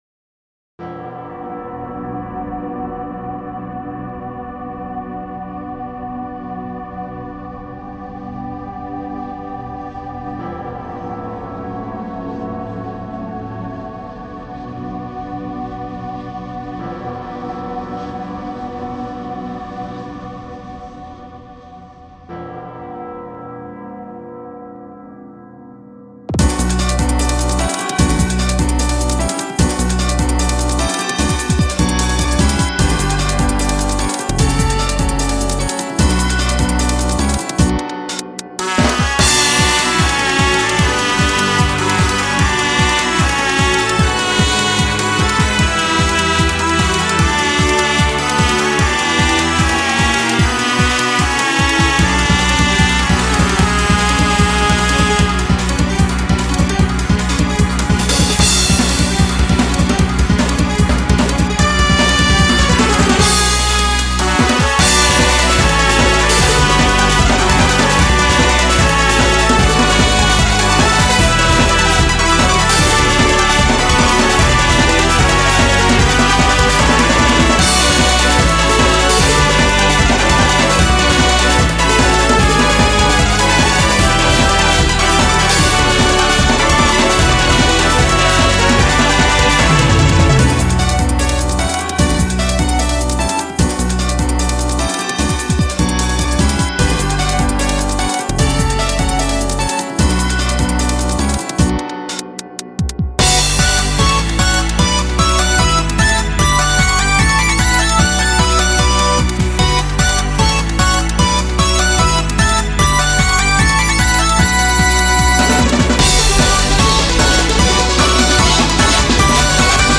Track 1-11 ... リマスタリングver